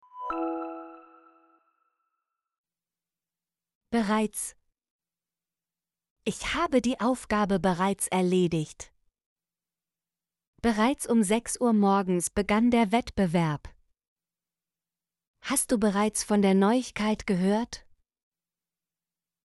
bereits - Example Sentences & Pronunciation, German Frequency List